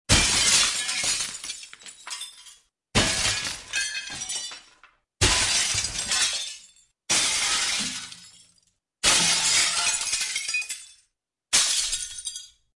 Download Breaking sound effect for free.
Breaking